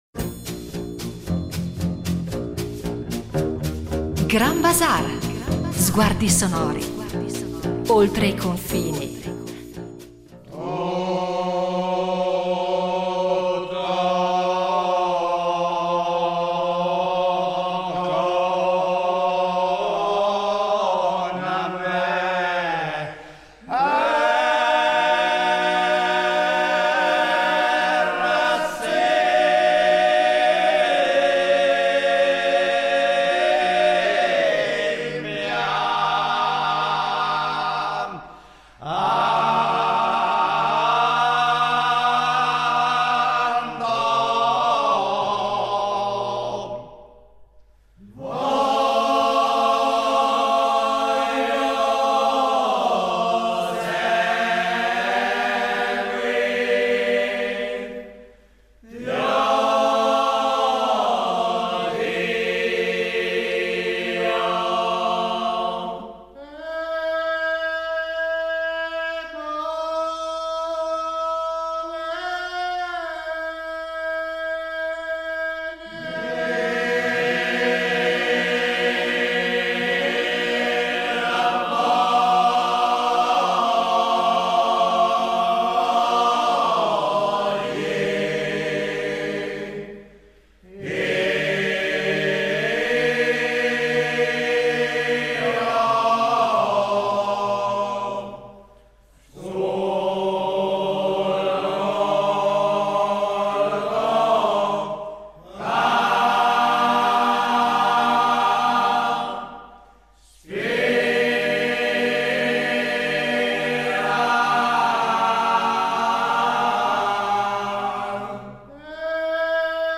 Canti penitenziali della Settimana Santa a Minori
Ma l’aspetto che rende davvero unica questa tradizione sono le melodie penitenziali intonate dai Battenti durante il loro cammino.